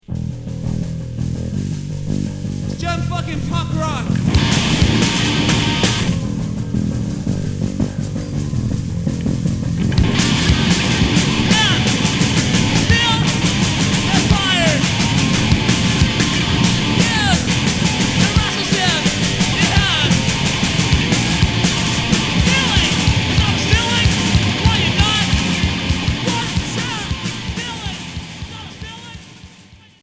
(Live' 95)
Hardcore